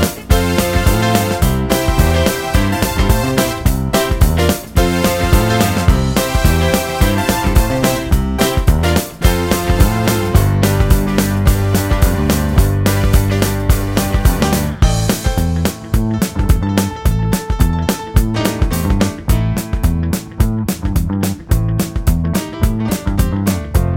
no Backing Vocals Soundtracks 2:48 Buy £1.50